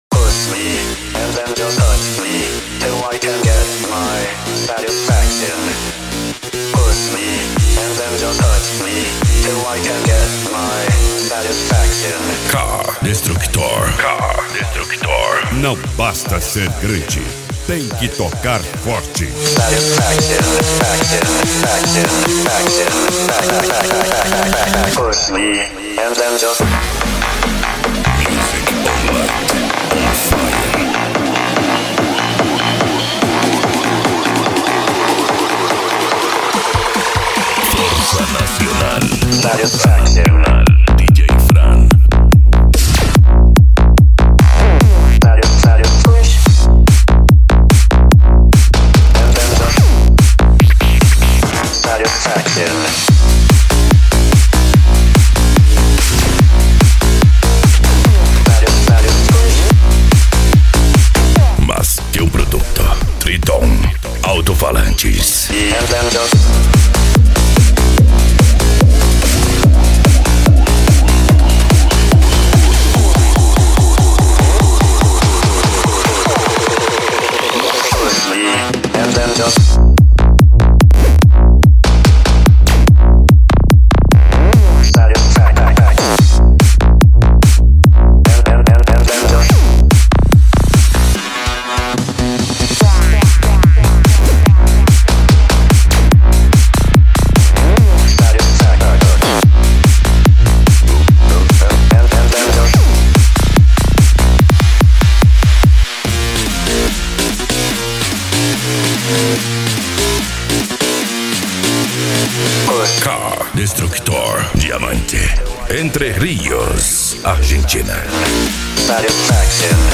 Bass
Psy Trance
Remix